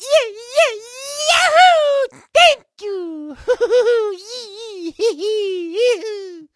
Mario cheers and jumps, makes a small guttural snort, thanks the reward, and giggles and waves while winning a trophy. From Mario Golf: Toadstool Tour.